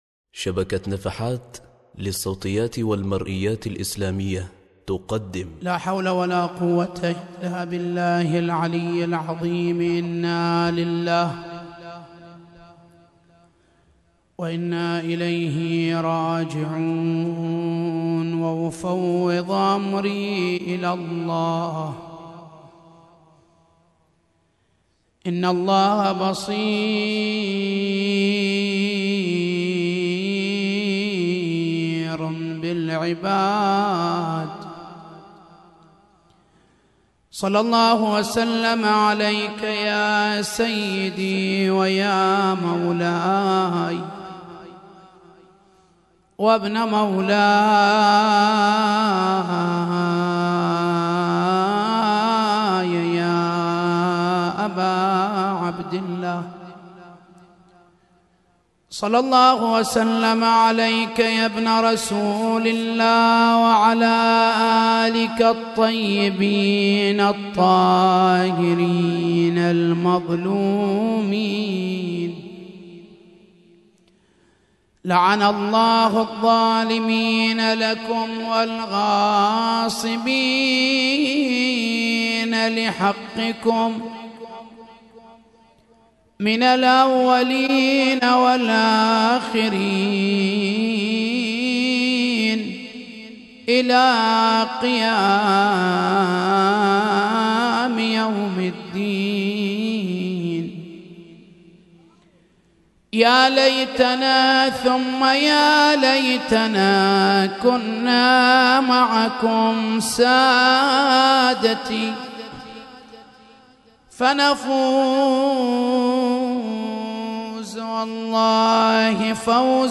ليلة 13 محرم 1436هـ – عنوان المحاضرة: مقومات السلم الإجتماعي